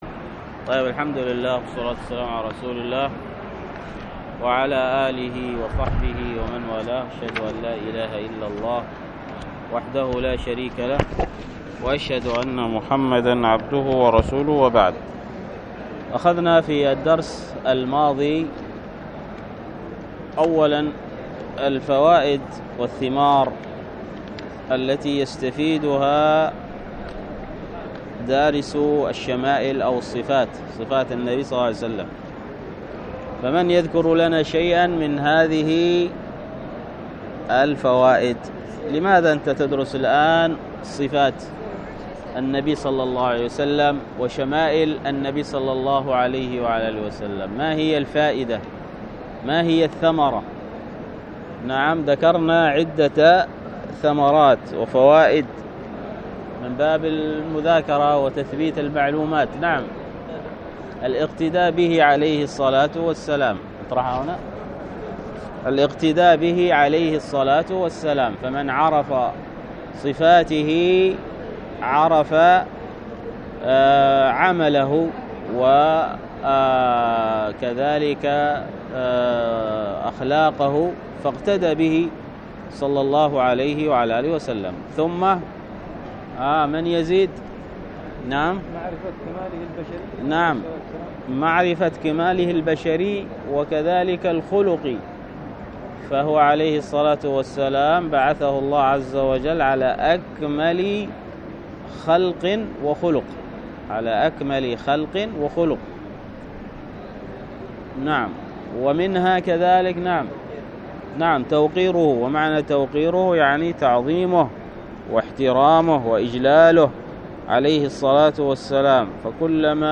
الدرس في شرح كتاب صفات النبي صلى الله عليه وسلم للعثيمين 1